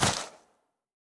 Fantasy Click (4).wav